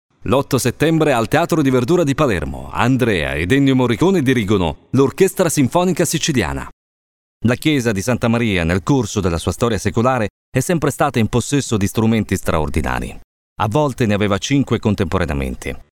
Voce maschile dinamica, brillante e versatile per comunicati rivolti sia ad un target giovane che meno giovane, si presta perfettamente per ogni tipo di comunicato.
Sprechprobe: Industrie (Muttersprache):